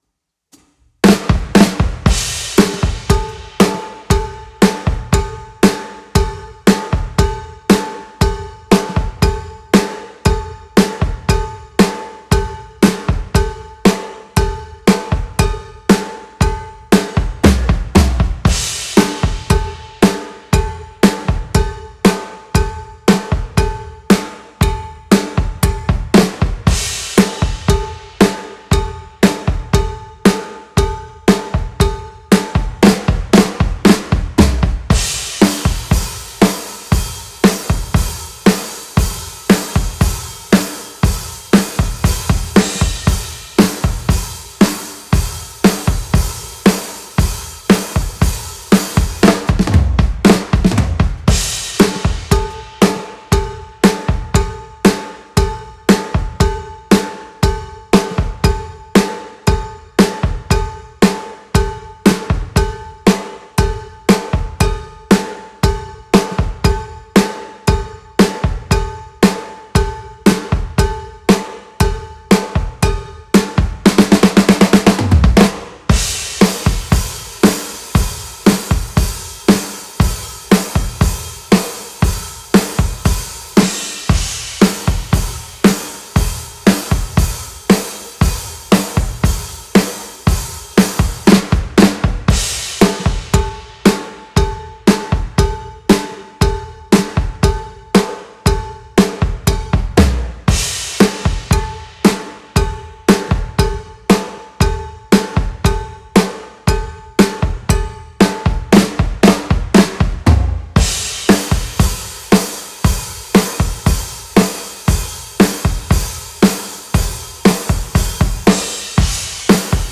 Rock
Genre:Rock, Grunge
Tempo:117 BPM (4/4)
Kit:Rogers 1983 XP8 24"
Mics:15 channels